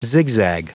Prononciation du mot zigzag en anglais (fichier audio)